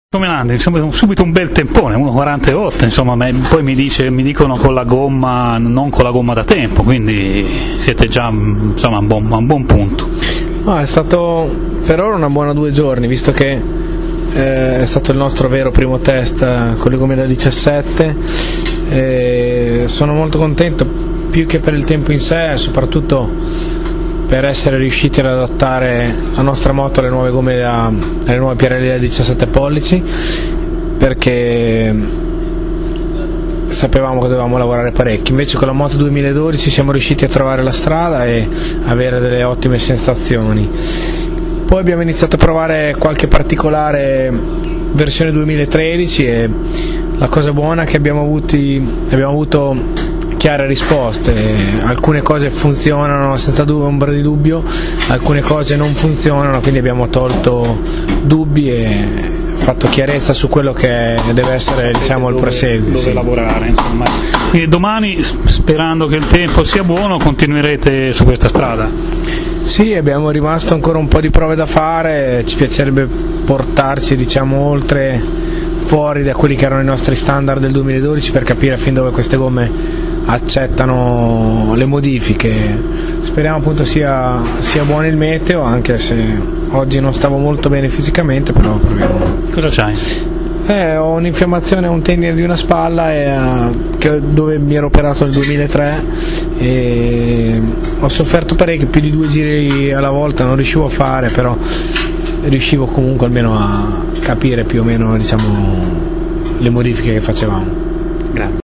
ascolta la sua intervista) ottenuto per di più con le gomme da gara ed in non perfette condizioni fisiche (un infiammazione alla spalla non gli consente di percorrere più di due, tre giri per volta), conferma che le prestazioni delle Superbike, almeno su questa pista, non sono certamente distanti da quelle delle GP.